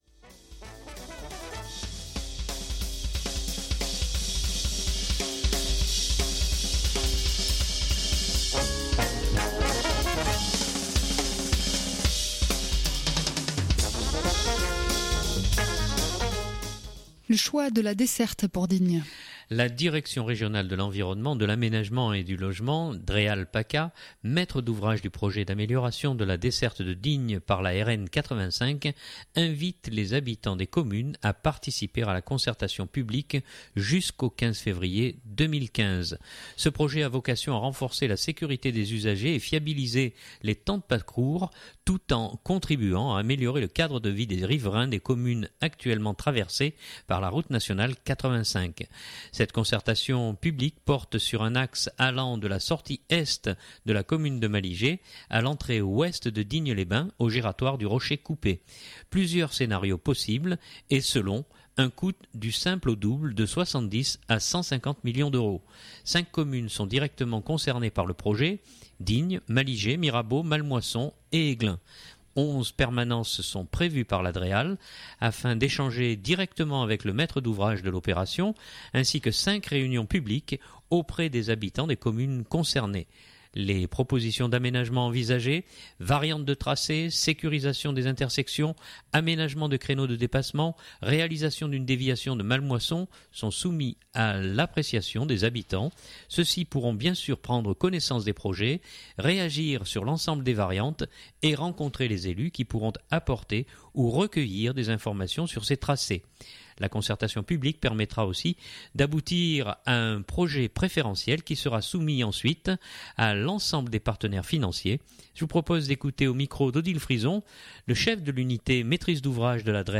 mais d’abord, la Préfète des Alpes de Haute-Provence.